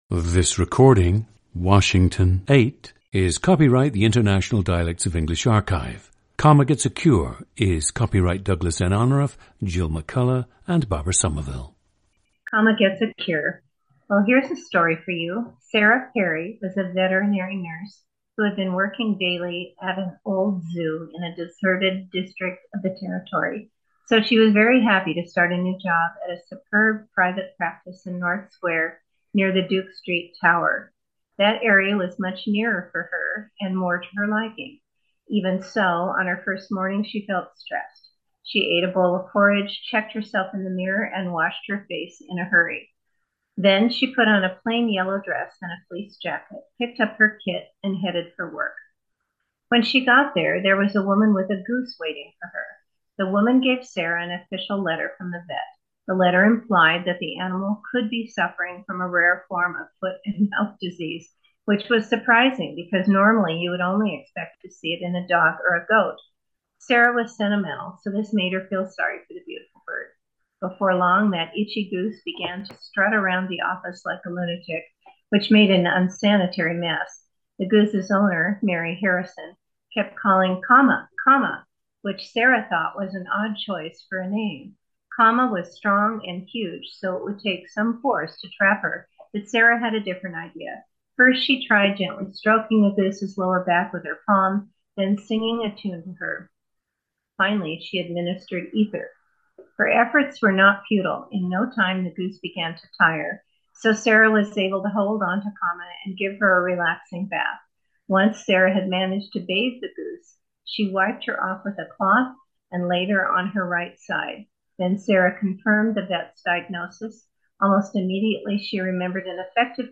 PLACE OF BIRTH: Walla Walla, Washington
GENDER: female
The subject spent a lot of time with grandparents who had also lived in Walla Walla all of their lives.
• Recordings of accent/dialect speakers from the region you select.